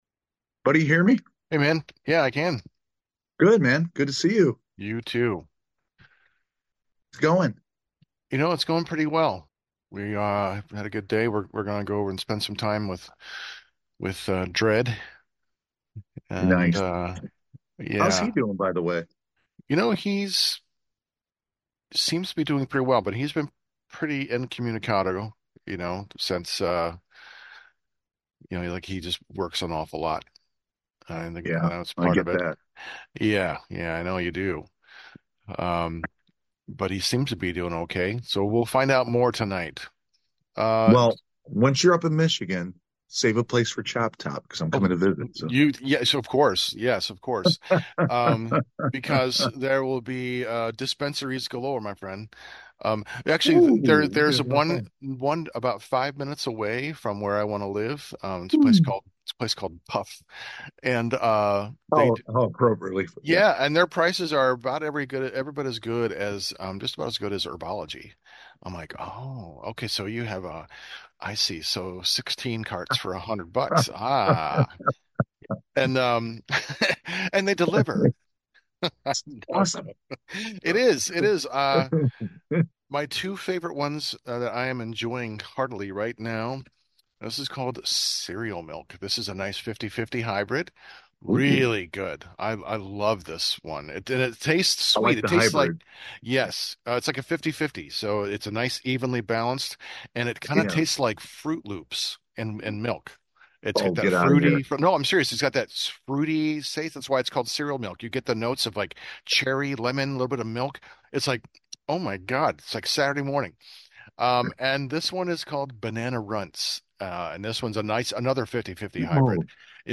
Your poorly drawn hosts talk about the Japanese anime movie PAPRIKA (2006) directed by Satoshi Kon. It’s a wild romp involving dreams and dreams within dreams and dreams commingling and dreams blending into reality. And it’s kind of a murder mystery.